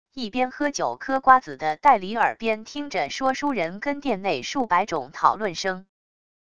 一边喝酒磕瓜子的代离耳边听着说书人跟店内数百种讨论声wav音频